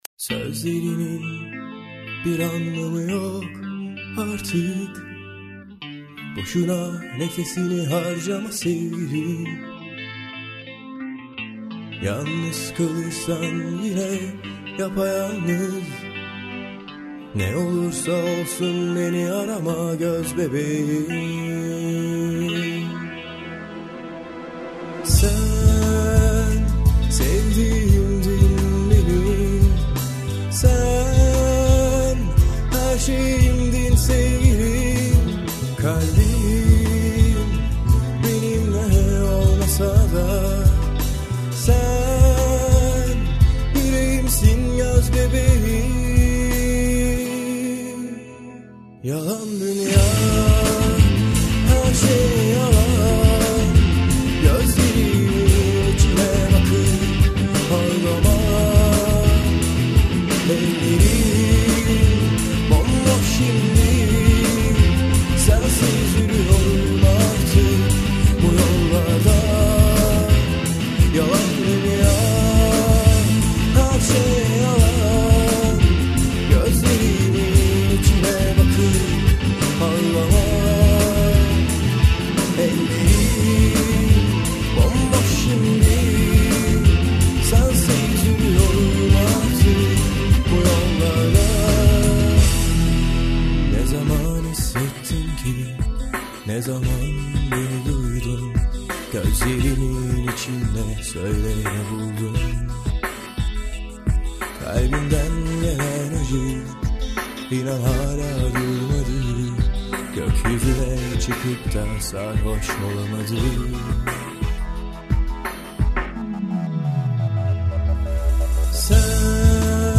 Modern Turkish Music
Alternative rock